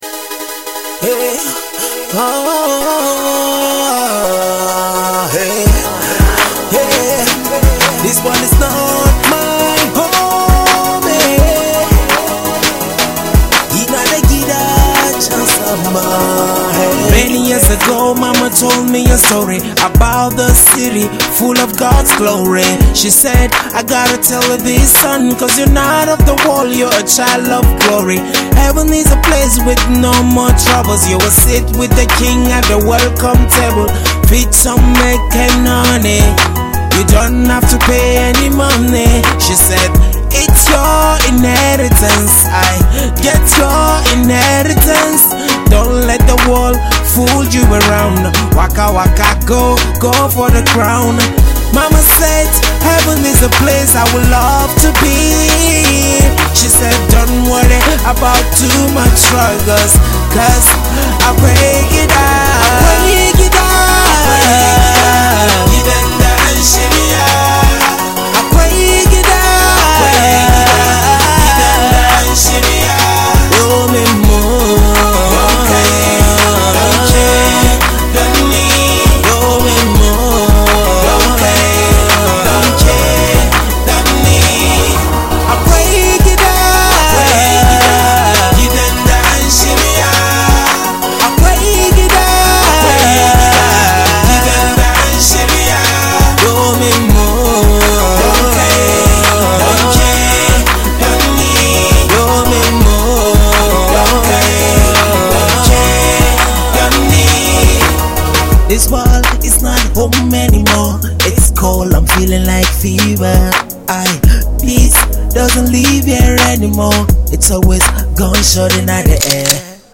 Christian song
Northern Nigerian Gospel Song